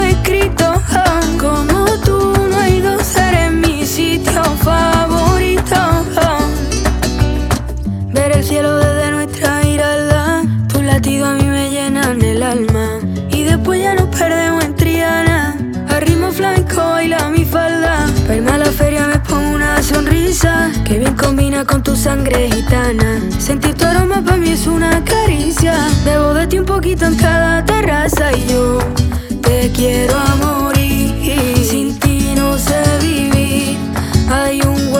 Жанр: Поп
# Pop